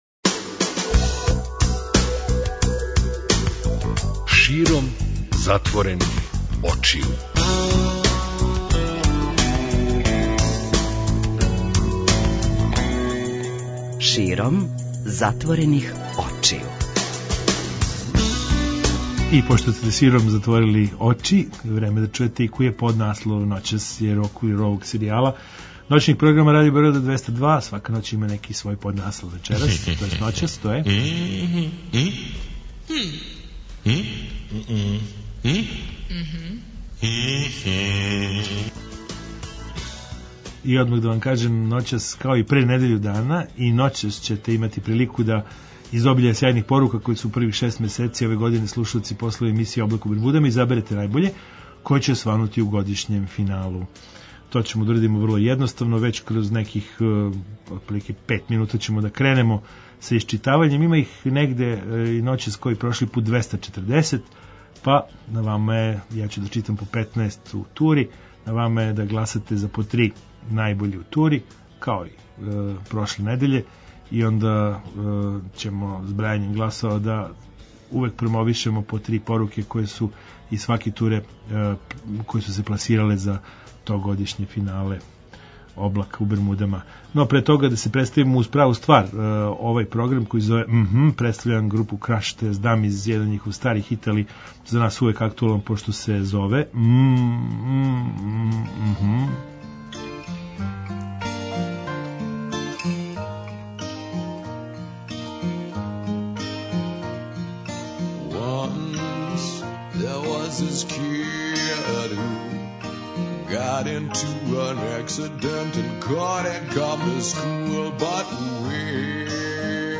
преузми : 54.15 MB Широм затворених очију Autor: Београд 202 Ноћни програм Београда 202 [ детаљније ] Све епизоде серијала Београд 202 Тешке боје Устанак Устанак Устанак Брза трака